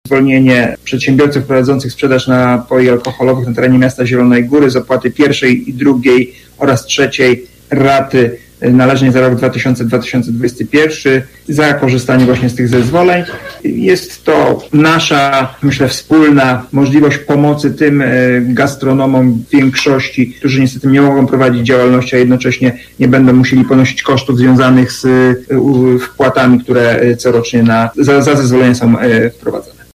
Potrzebę przyjęcia uchwały argumentował wiceprezydent Dariusz Lesicki: